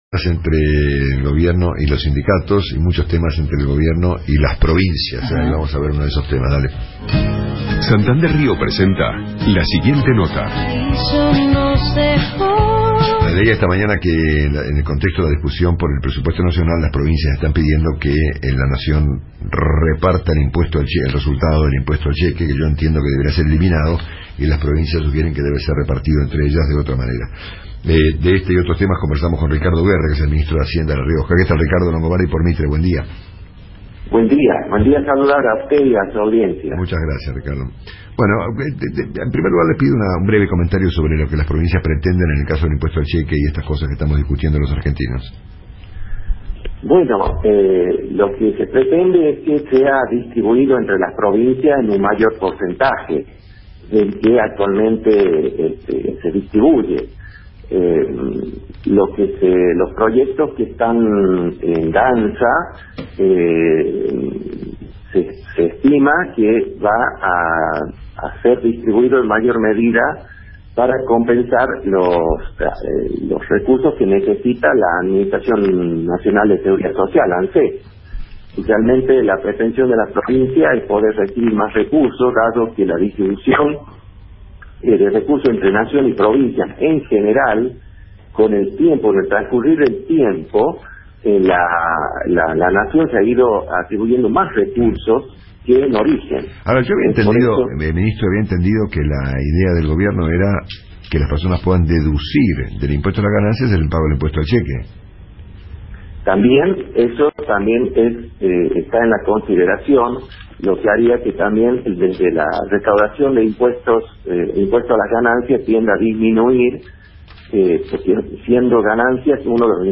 Entrevista con Ricardo Guerra por Radio Mitre
*Bio: ministro de Hacienda de La Rioja.